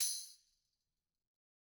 Tamb1-Hit_v2_rr1_Sum.wav